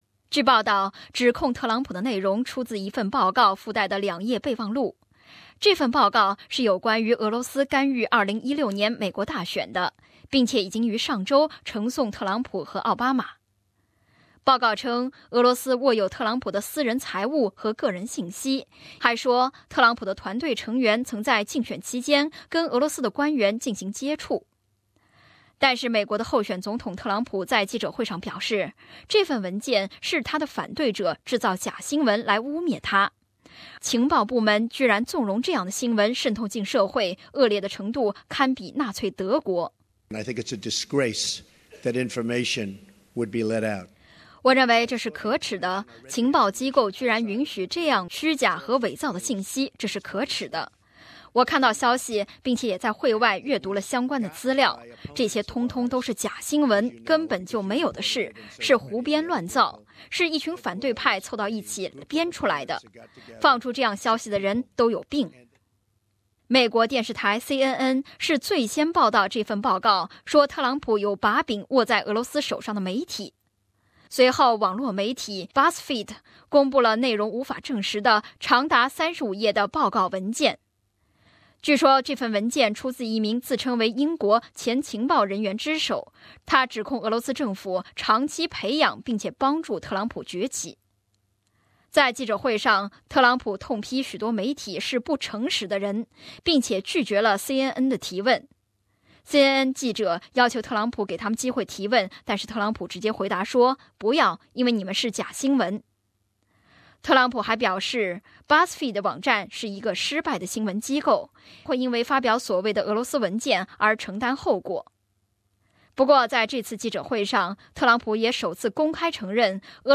President-elect Donald Trump holds a news conference in the lobby of Trump Tower in New York (AAP)